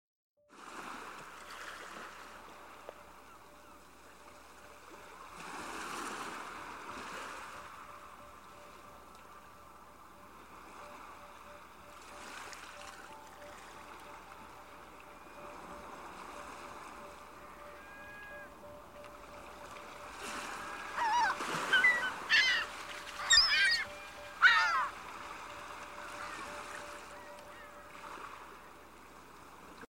Secret waves sounds that calm sound effects free download
Secret waves sounds that calm the mind and soothe the soul 🌊🌊🌊 Take a break from the stresses of the day with this beautiful Relaxing Meditation Waves Sound! This audio will help you to relax and de-stress, and will help to improve your focus and concentration.